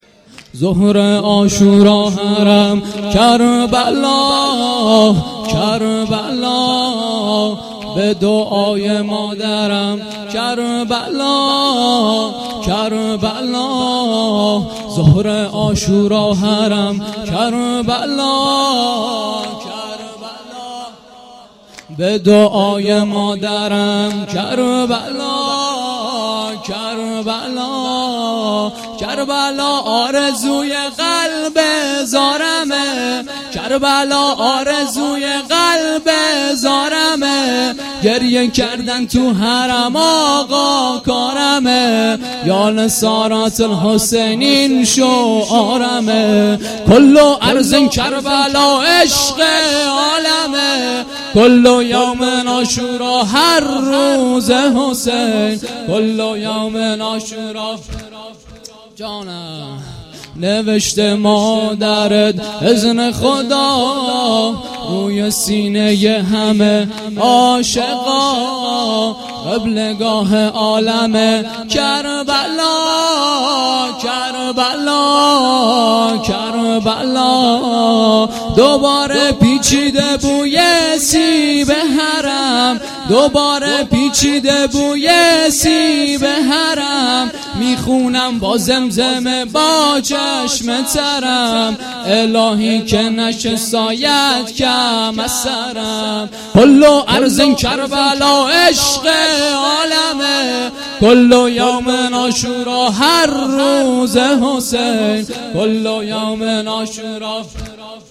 گزارش صوتی جلسه هفتگی 28 محرم الحرام